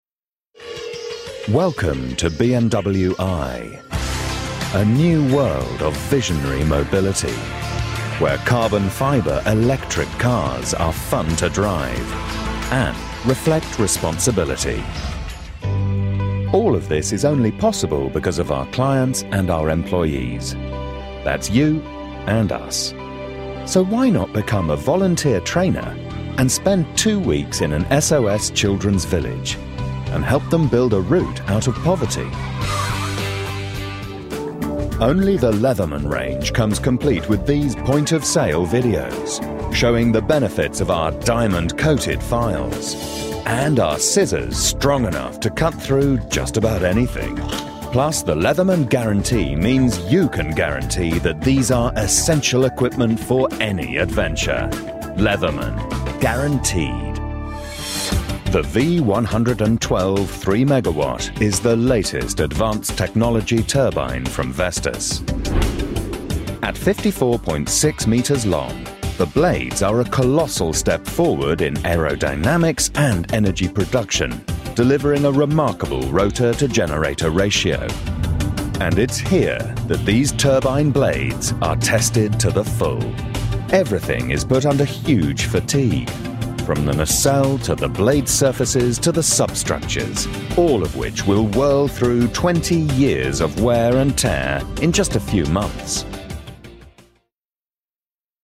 BBC British MVO. A voice people warm to, believe in and laugh with.
Sprechprobe: Industrie (Muttersprache):
From deep and dark to wry humour. Intelligent voiceover and skilled narrator.